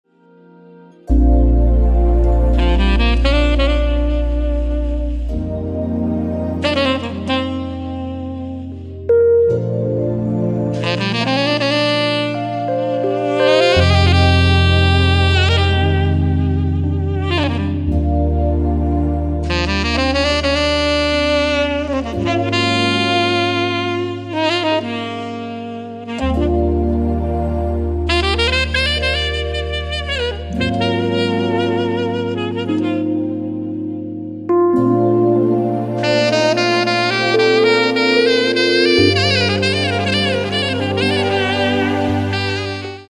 Saxophon & coole Sounds
Tenor-Saxophon